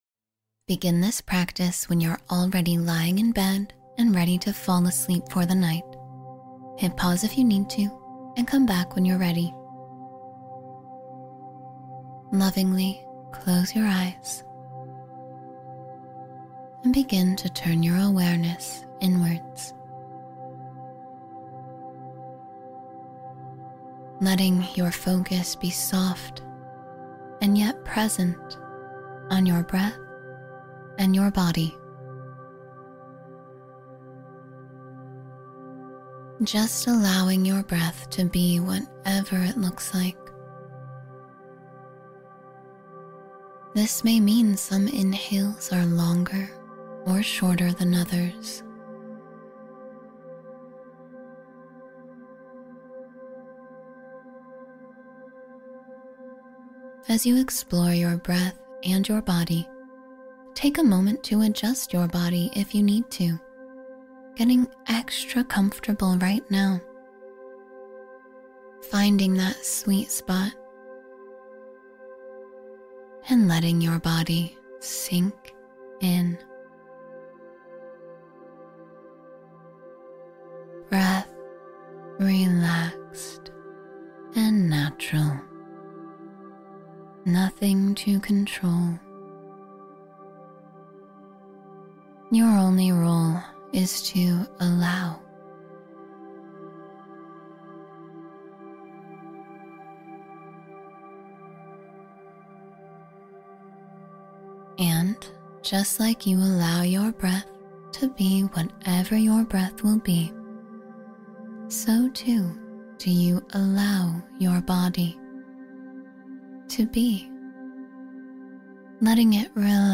Clear Your Mind and Open to Receive — Meditation for Clarity and Receptivity